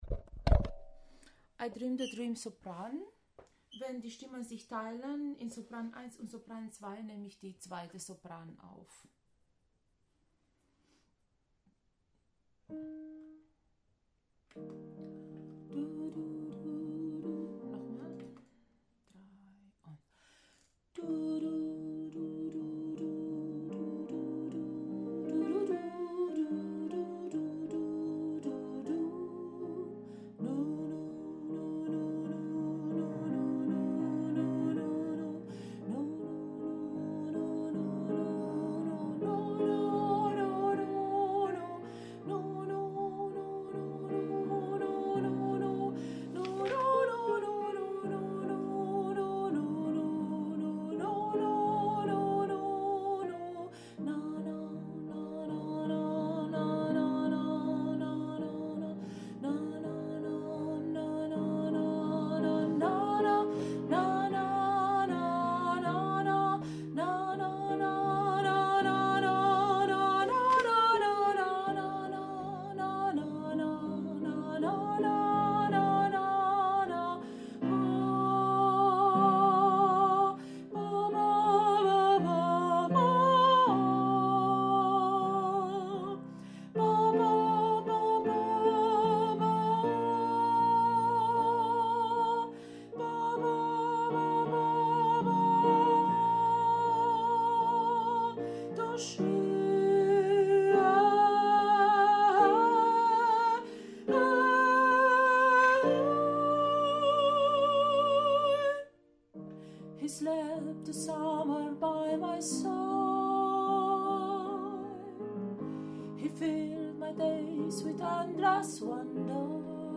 I dreamed a dream – Sopran 1+2